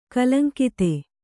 ♪ kalaŋkite